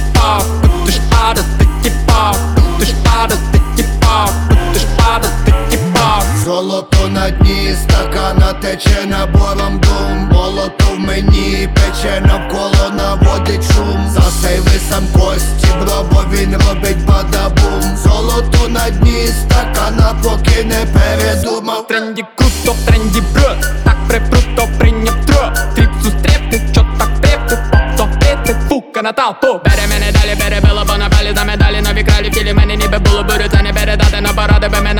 Жанр: Поп / Украинские
# Pop